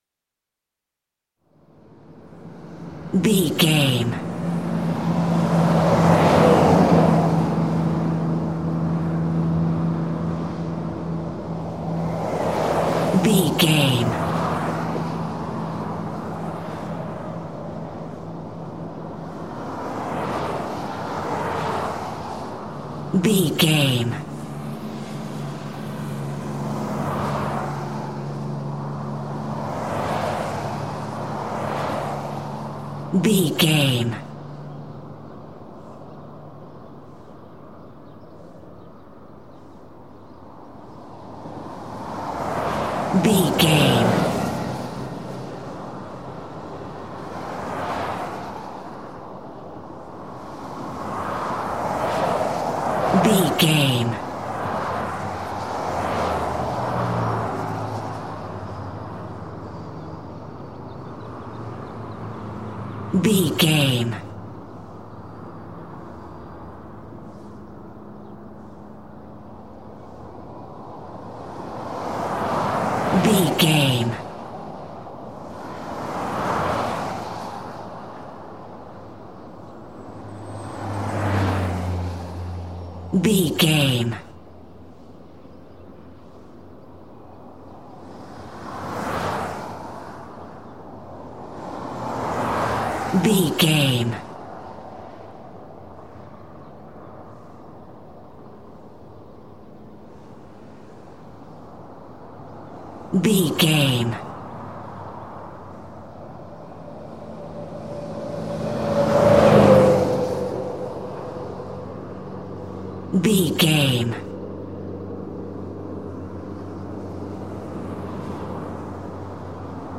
City small avenue vehicles pass by
Sound Effects
urban
ambience